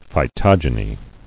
[phy·tog·e·ny]